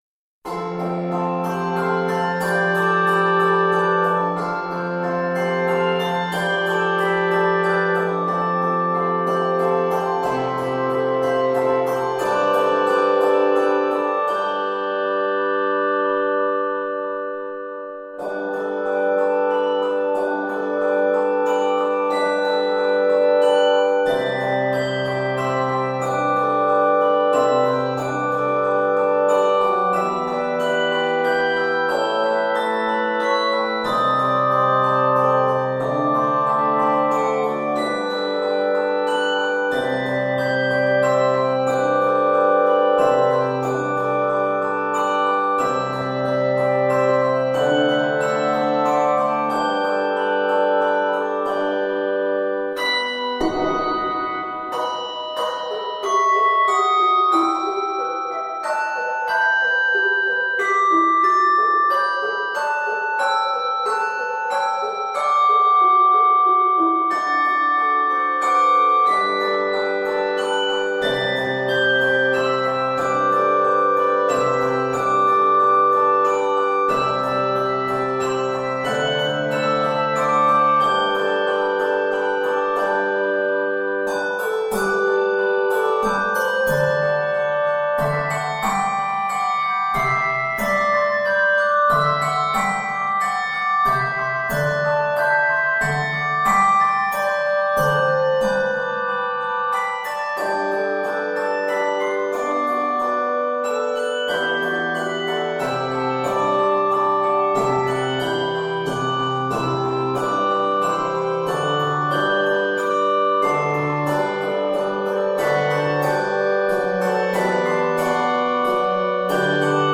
Scored in G Major, this exquisite medley is 92 measures.